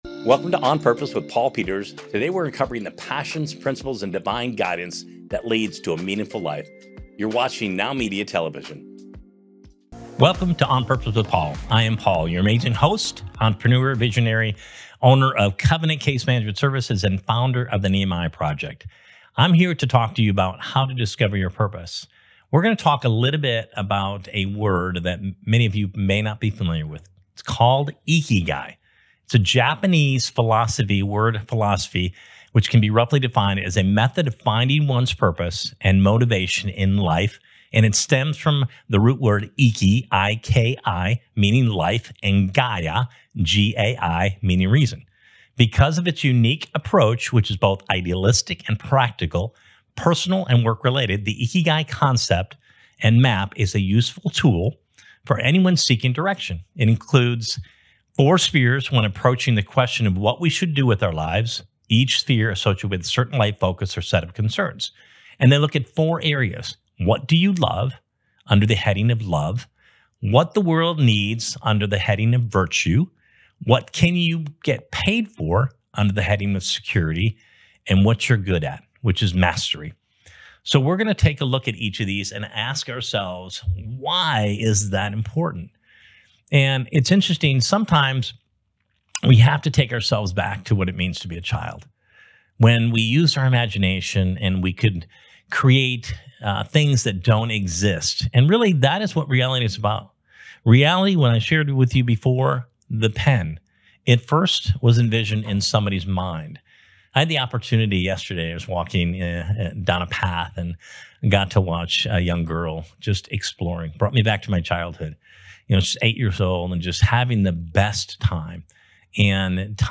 This episode also features an inspiring conversation